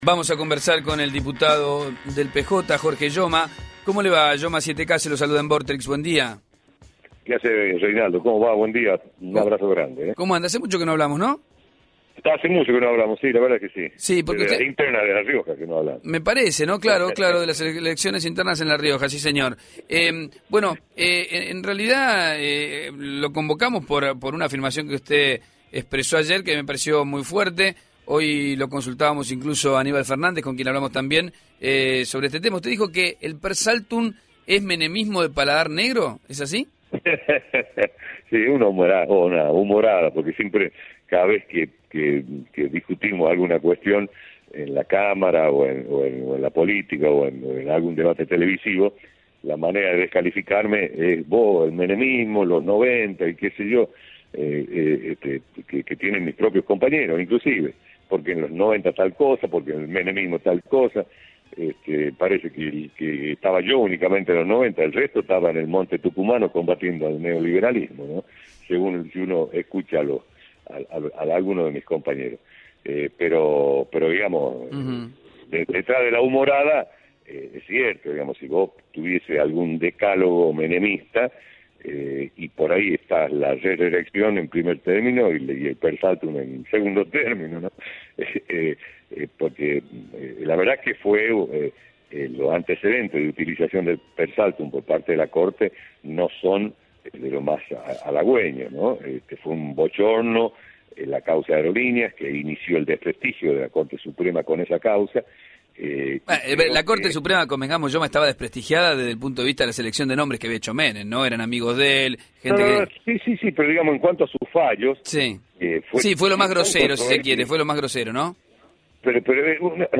Jorge Yoma, diputado nacional, por Radio Vorterix
jorge-yoma-diputado-nacional-por-radio-vorterix.mp3